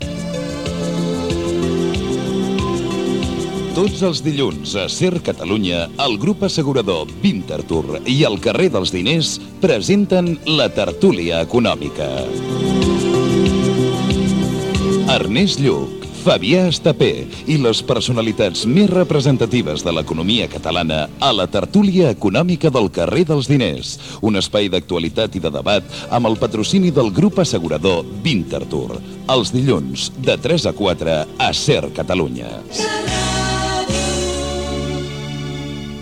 Promoció del programa
Divulgació